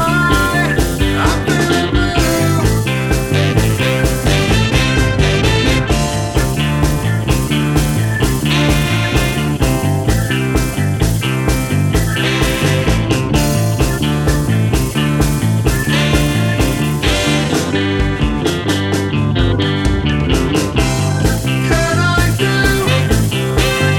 Two Semitones Down Pop (1960s) 2:56 Buy £1.50